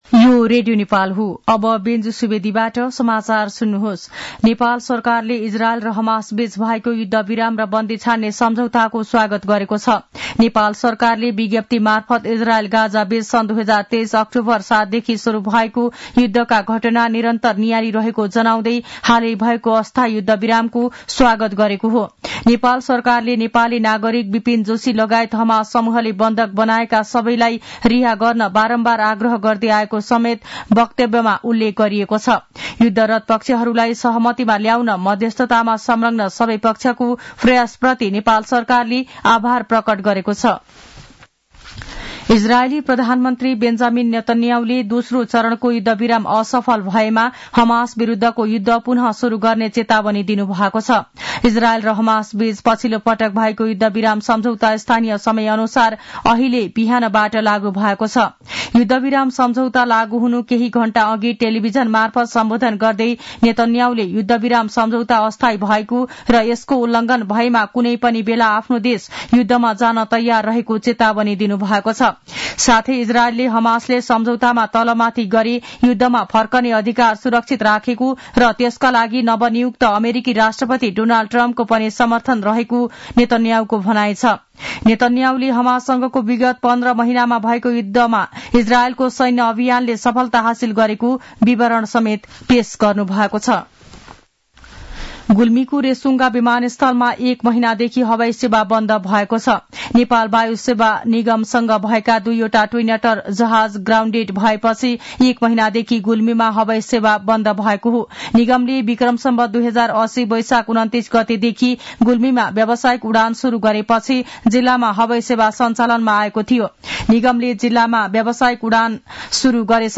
An online outlet of Nepal's national radio broadcaster
मध्यान्ह १२ बजेको नेपाली समाचार : ७ माघ , २०८१